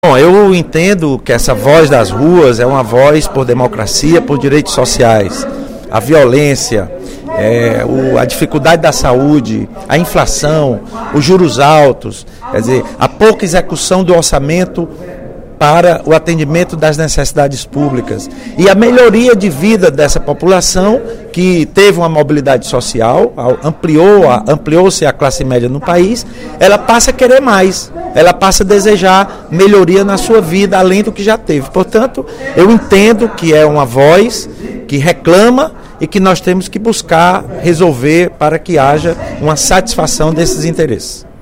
O deputado Lula Morais (PCdoB) chamou atenção, nesta terça-feira (18/06), durante o primeiro expediente da sessão plenária, para a importância das manifestações realizadas ontem em diversas cidades brasileiras.